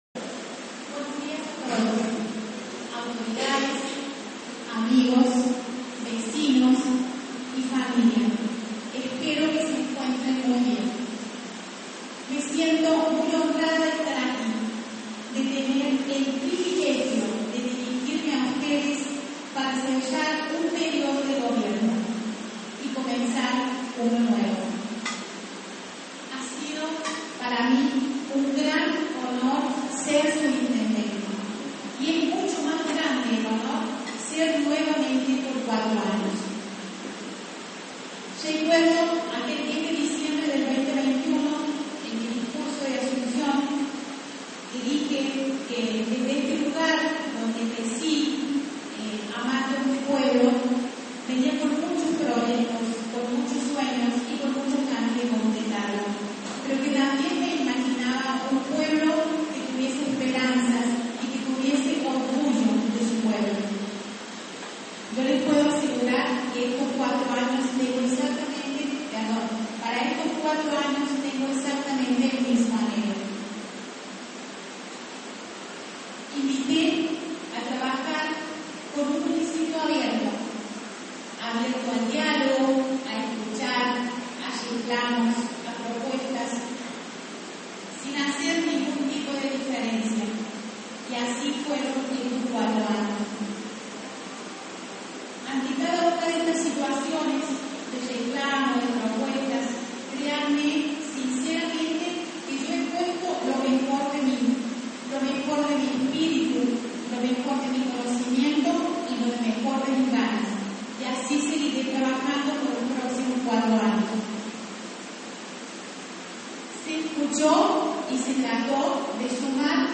En un salón municipal colmado de vecinos, instituciones y autoridades provinciales y regionales, Carolina Salinas juró este viernes su segundo mandato al frente del municipio, en un acto que combinó emoción, balance y una clara apuesta a profundizar el trabajo comunitario.